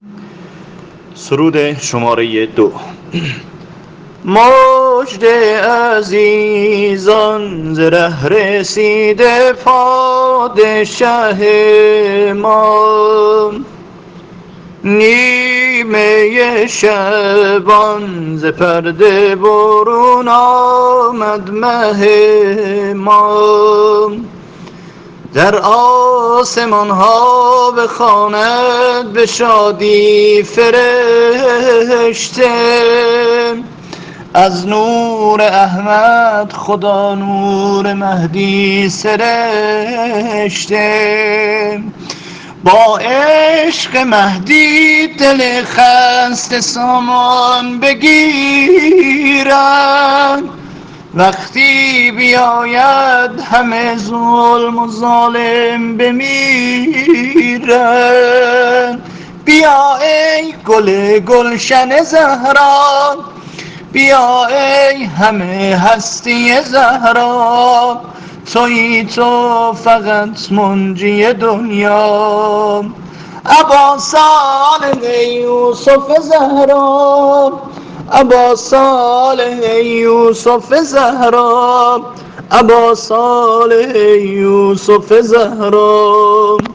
آواز اصفهان